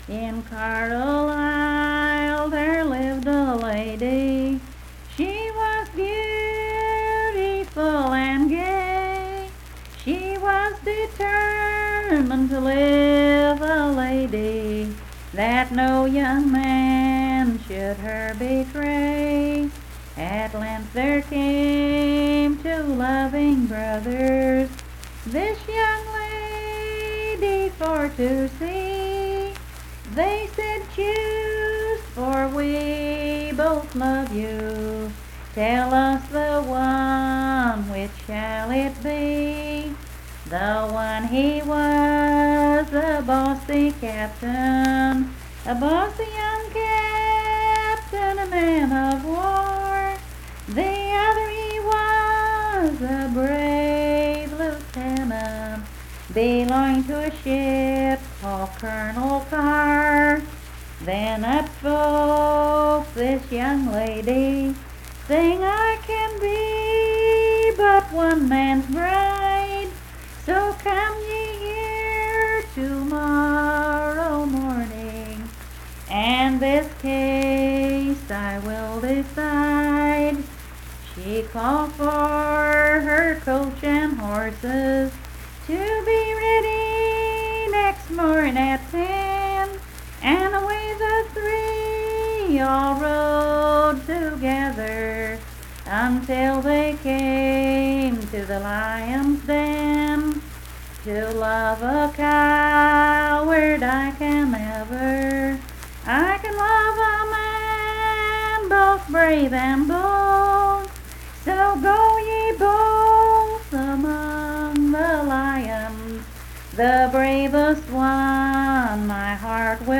Unaccompanied vocal music
Verse-refrain 11(4).
Performed in Coalfax, Marion County, WV.
Voice (sung)